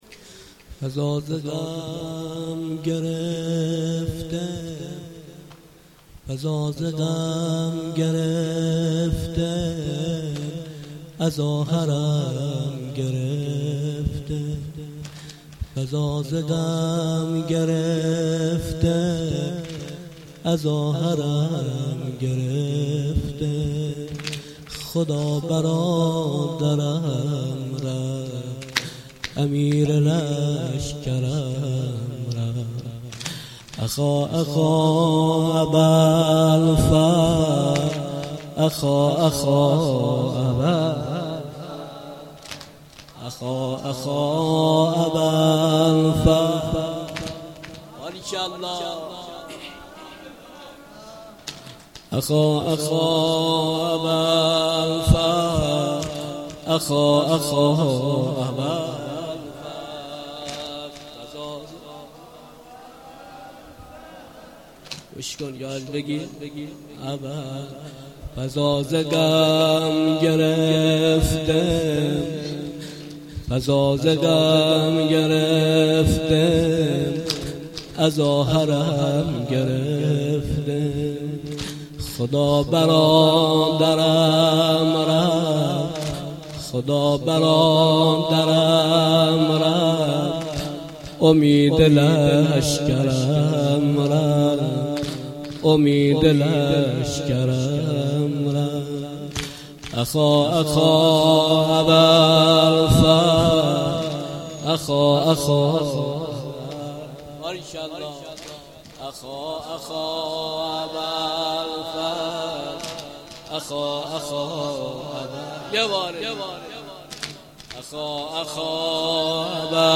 گزارش صوتی جلسه هفتگی2دیماه